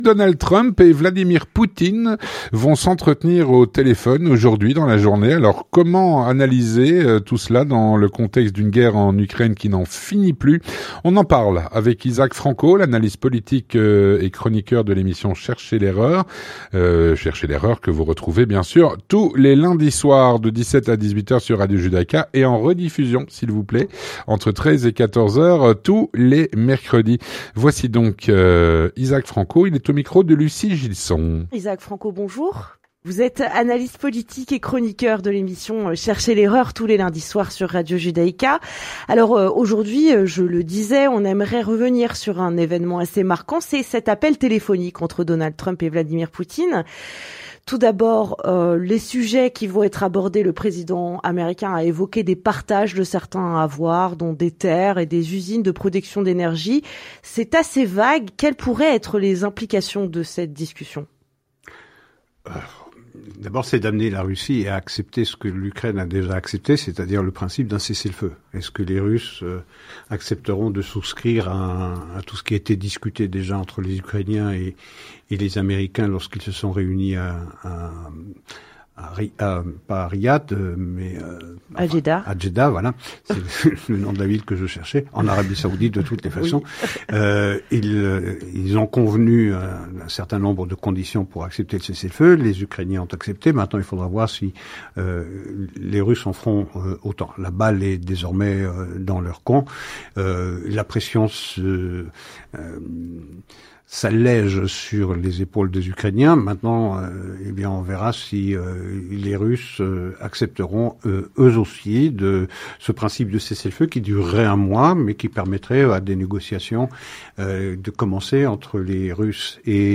L'entretien du 18H - Donald Trump et Vladimir Poutine vont s’entretenir au téléphone dans la journée.